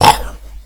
enem_crooked_hit1.wav